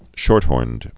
(shôrthôrnd)